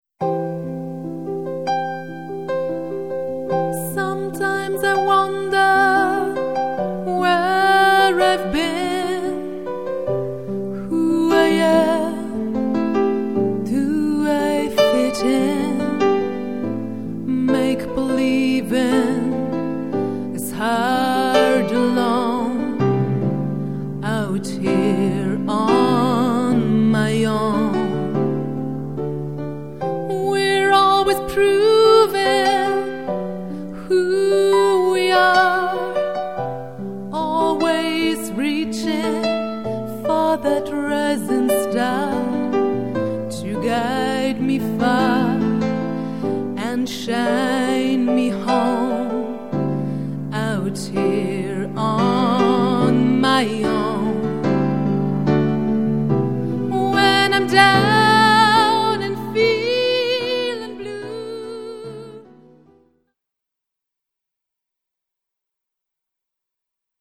aus den Sparten Pop, Musical und Deutsch-Chanson